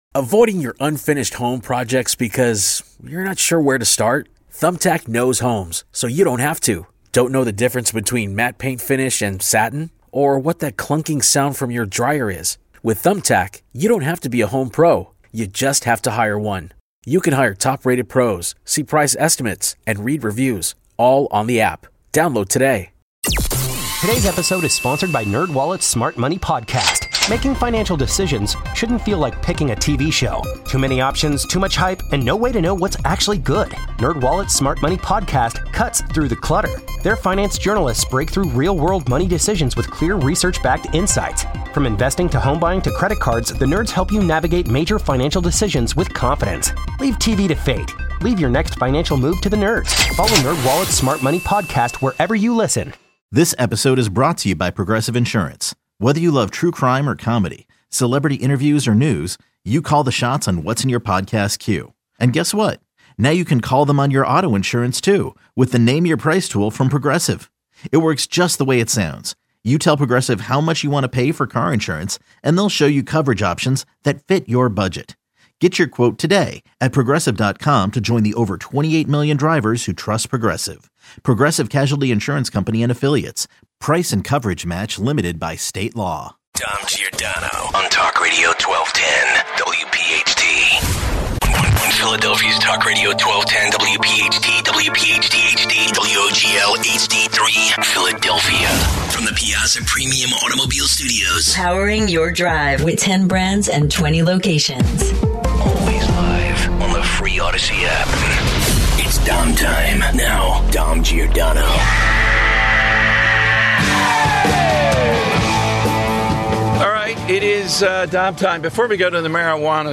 Your calls. 240 - Trump makes a Biden comparison. 250 - The Lightning Round!